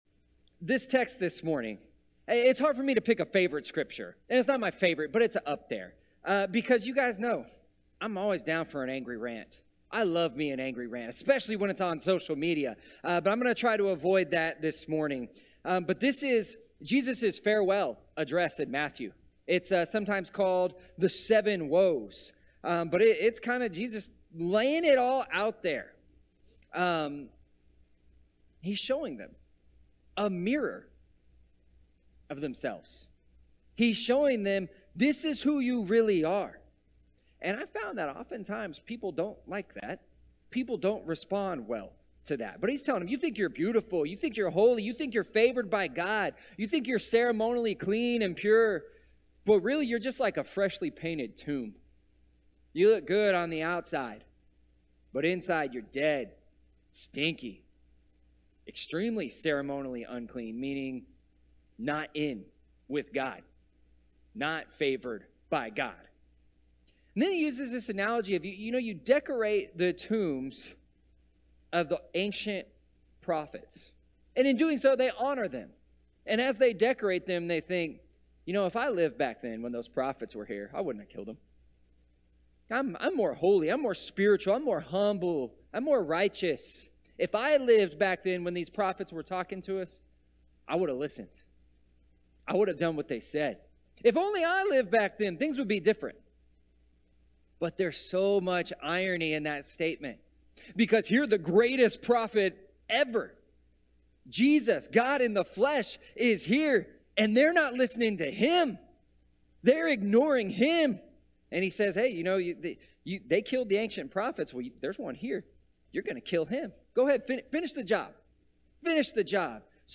This week's bulletin - 3/26/2023 More from the series: The Mirror series ← Back to all sermons